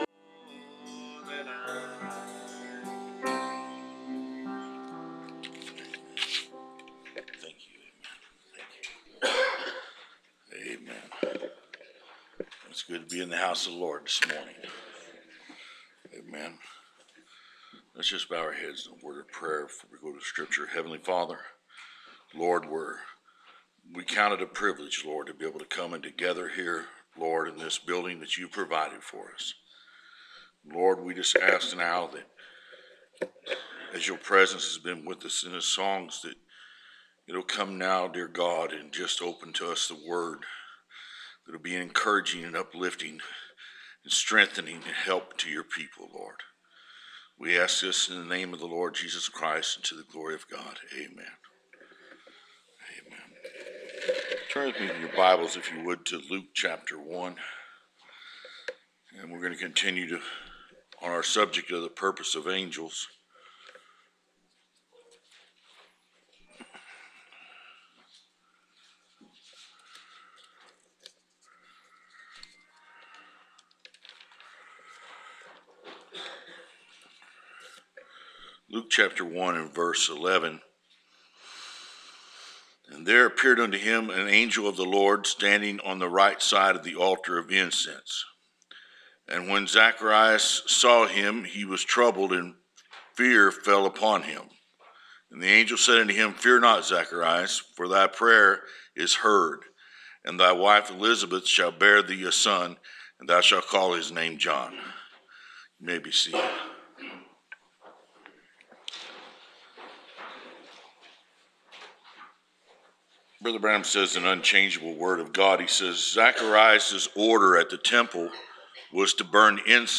Preached January 15, 2017